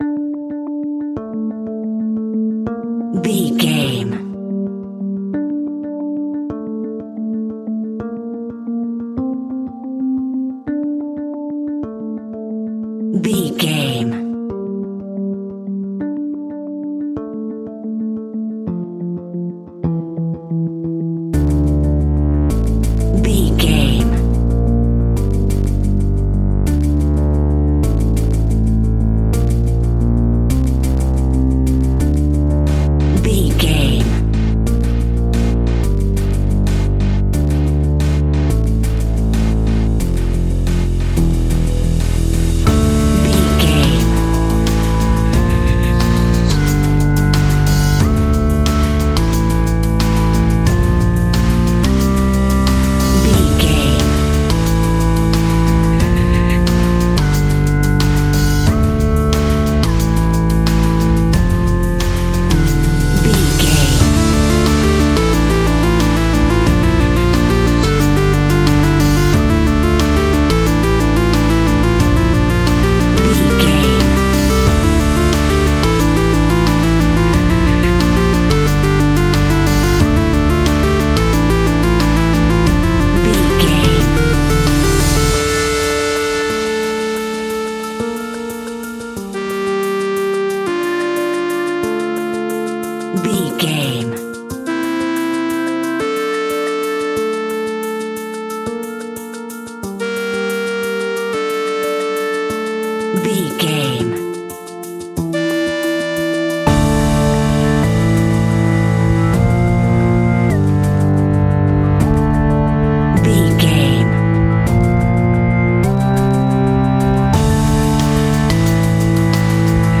Aeolian/Minor
scary
tension
ominous
dark
haunting
eerie
drums
synthesiser
ticking
electronic music
electronic instrumentals